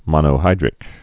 (mŏnō-hīdrĭk)